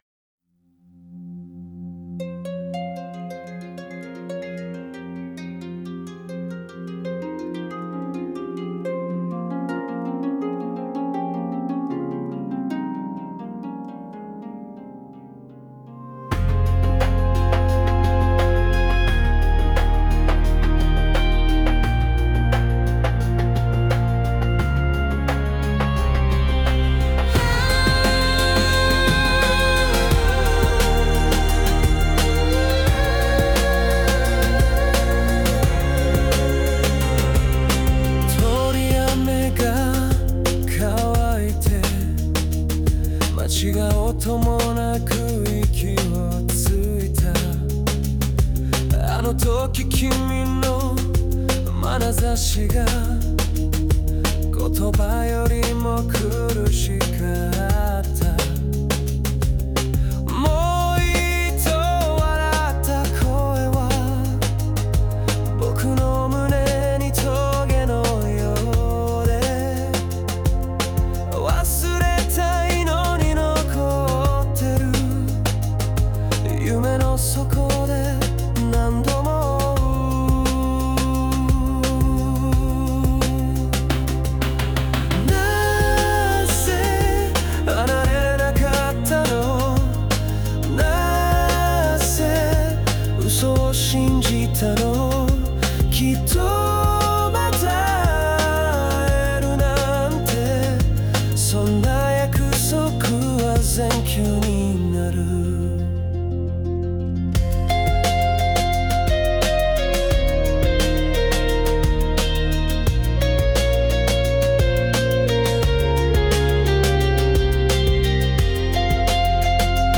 オリジナル曲♪
儚い声で歌われることで、傷つきながらも優しく誰かを想い続ける心情が、より深く表現されています。